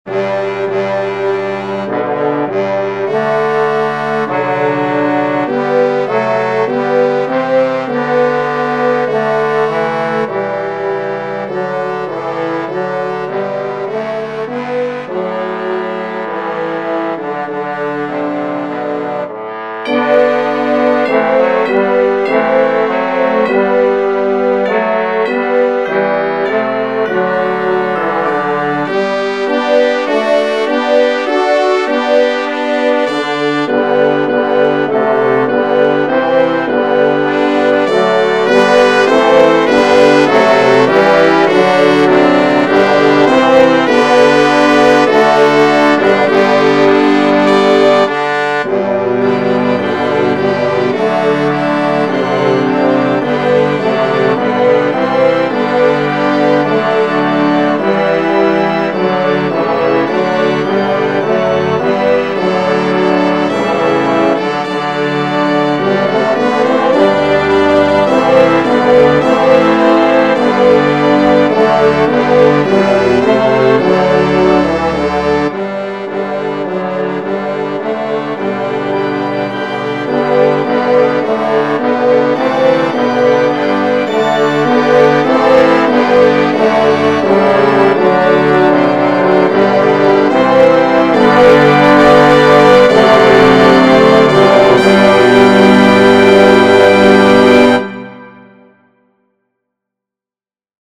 Voicing/Instrumentation: Clarinet , Organ/Organ Accompaniment , Trombone , Trumpet We also have other 45 arrangements of " Oh, Come All Ye Faithful ".